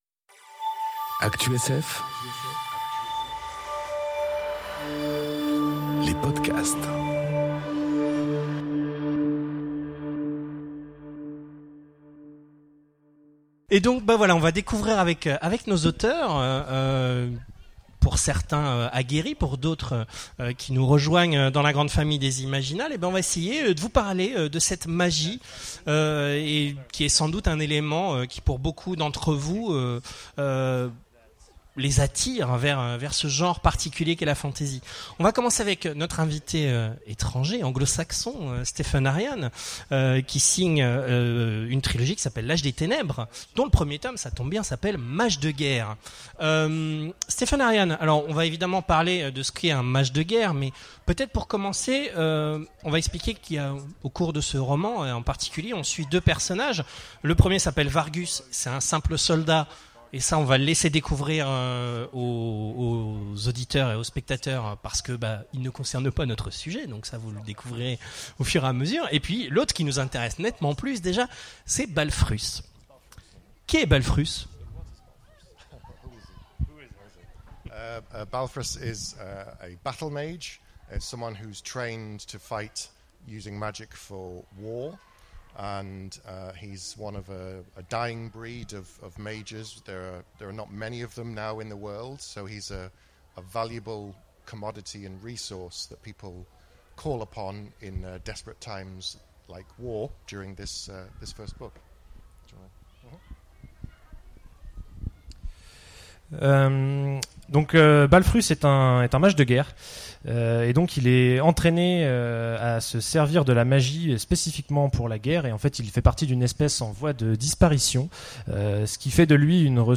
Conférence Écrivains, nous sommes tous... des magiciens enregistrée aux Imaginales 2018